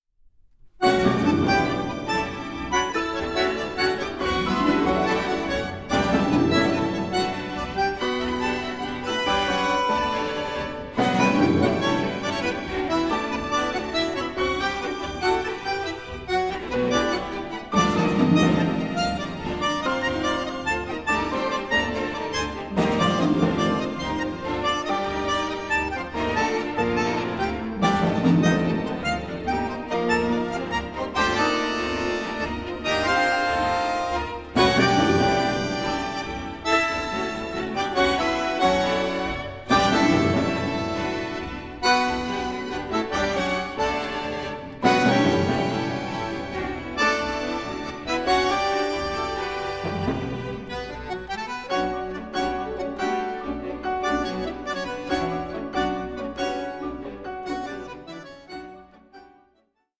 Concerto for bandoneón, string instruments and percussion